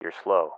LSO-Slow.ogg